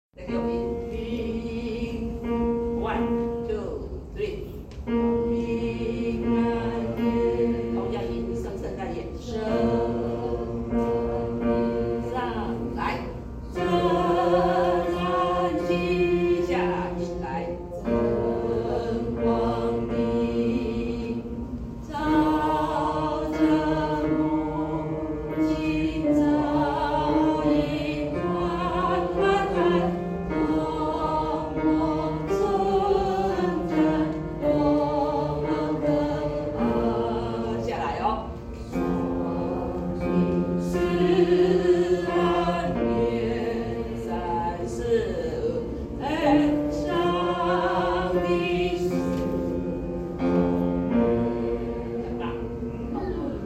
2024聖誕點燈音樂會百人詩班詩歌
平安夜  聖誕夜 (華語) 男低音(Bass)：
平安夜第四部教學.mp3